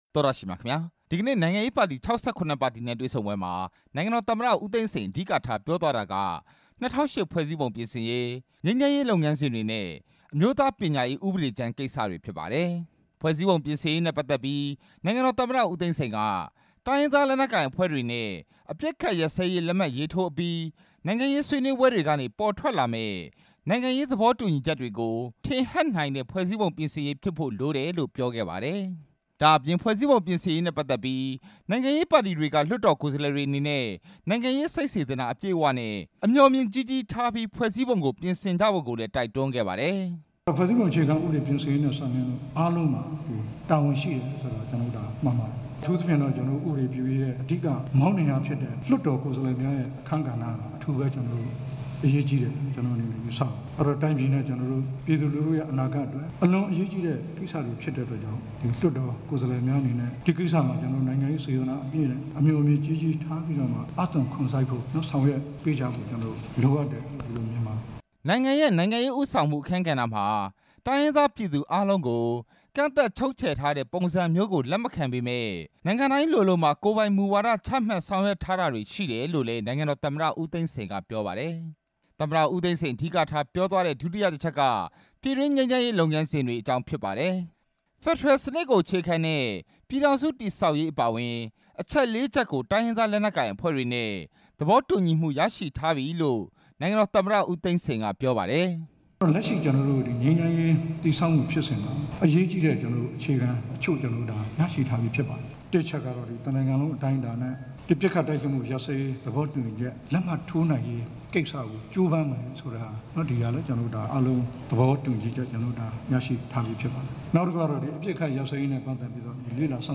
ရန်ကုန်မြို့ ရန်ကုန်တိုင်းဒေသကြီး လွှတ်တော် မှာ ပြည်ထောင်စု အစိုးရအဖွဲ့နဲ့ နိုင်ငံရေးပါတီ ၆၇ ပါတီ ခေါင်းဆောင်တွေ ဒီကနေ့တွေ့ဆုံပွဲမှာ နိုင်ငံတော် သမ္မတ ဦးသိန်းစိန်က အခုလိုပြောကြားခဲ့တာ ဖြစ် ပါတယ်။